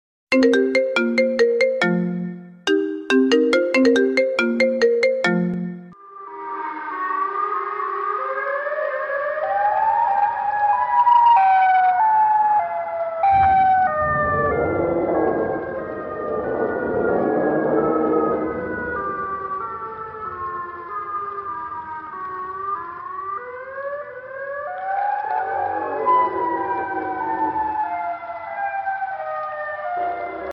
📌 Disclaimer: This is a fun and fictional fake call video not affiliated with any official character or franchise.